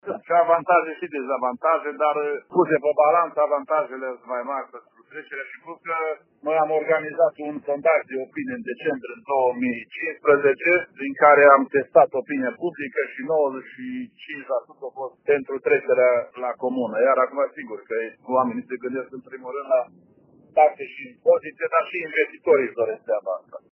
Un sondaj de opinie arată că locuitorii sunt de acord cu această transformare, spune primarul din Recaş, Pavel Teodor:
pavel-teodor-referendum-.mp3